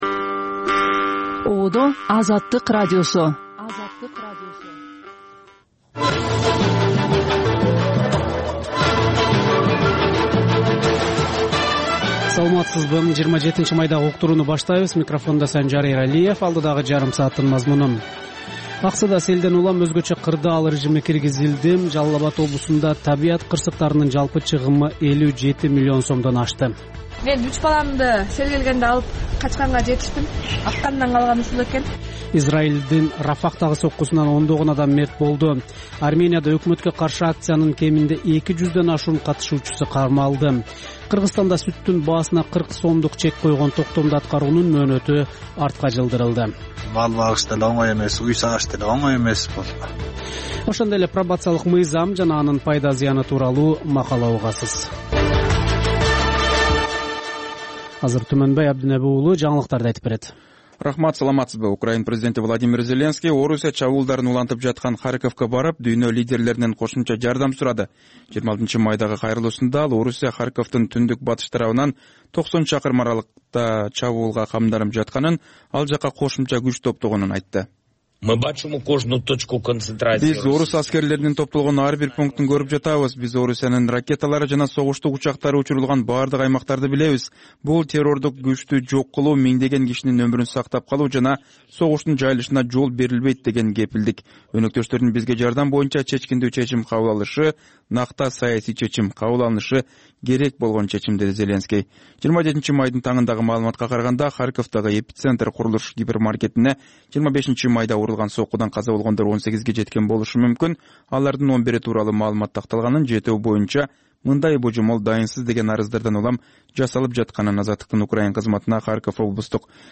Бул үналгы берүү ар күнү Бишкек убакыты боюнча саат 19:00дан 20:00га чейин обого түз чыгат.